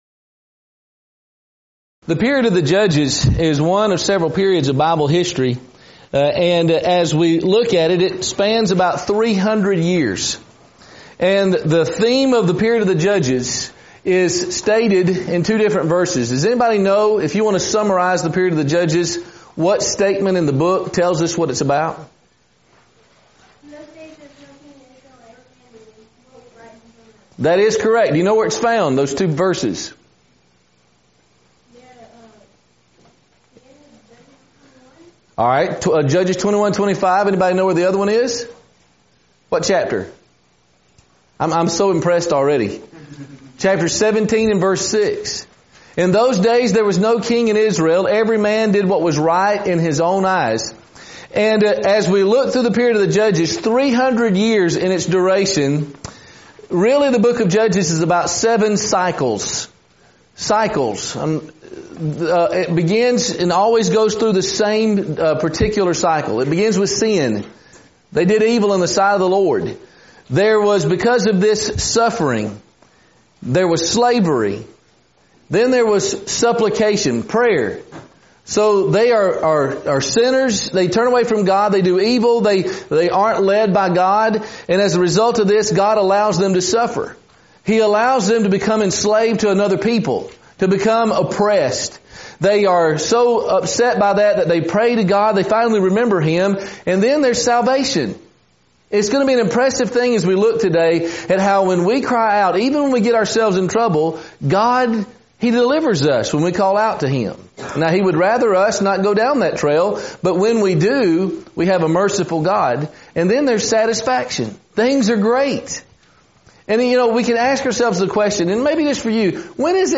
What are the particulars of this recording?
Event: 2018 Focal Point Theme/Title: Preacher's Workshop